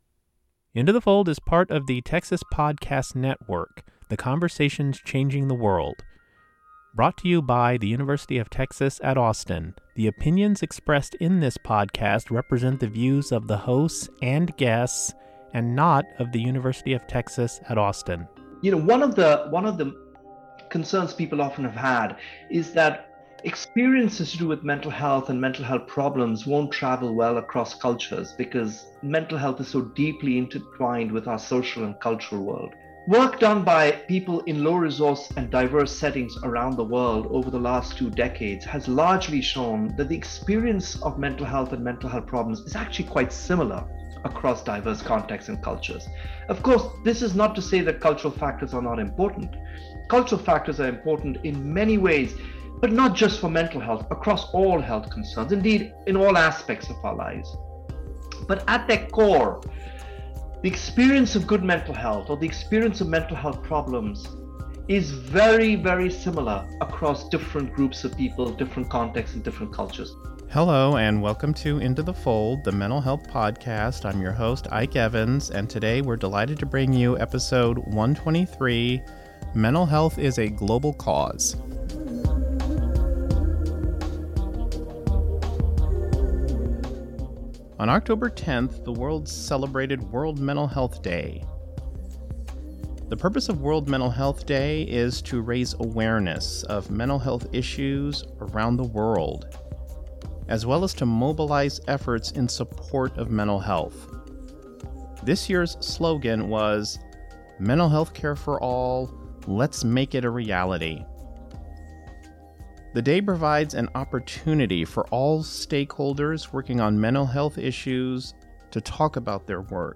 For this episode, we talk to two experts who have devoted their lives to global mental health.